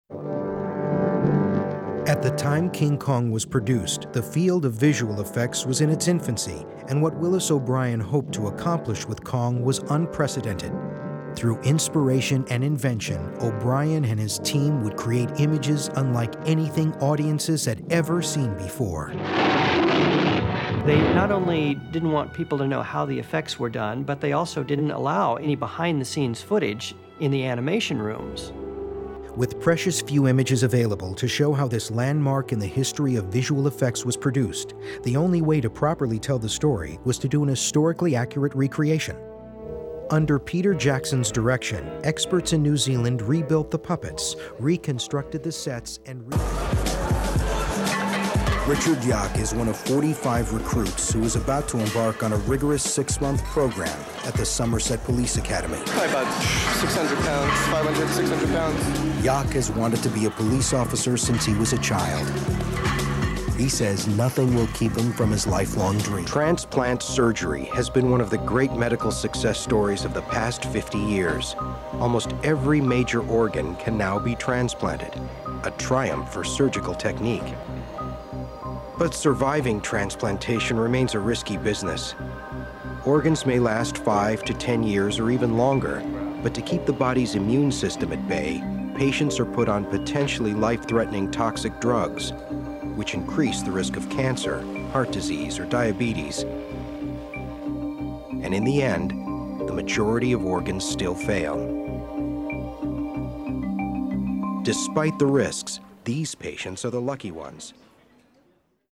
Male VOs
Listen/Download – Narration